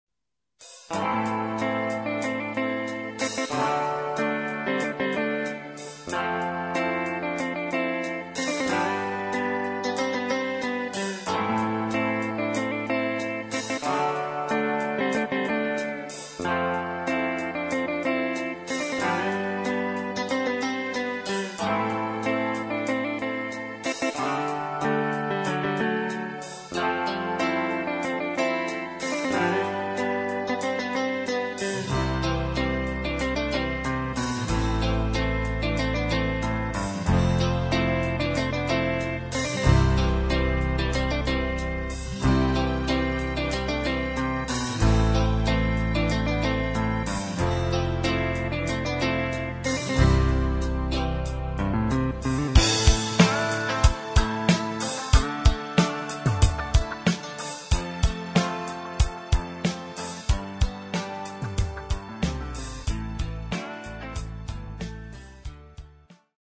• Music Genre: R&B/Soul